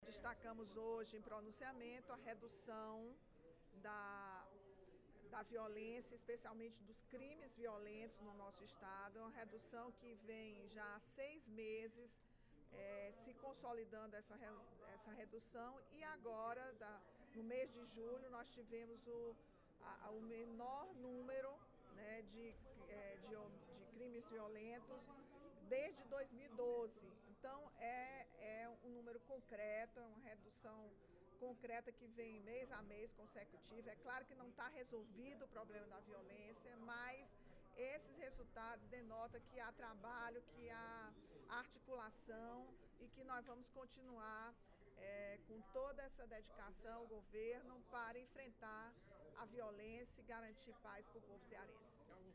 A deputada Rachel Marques (PT) destacou a redução do número de crimes violentos letais, pelo sexto mês consecutivo, no Ceará. O assunto foi abordado no segundo expediente da sessão plenária desta quarta-feira (05/08).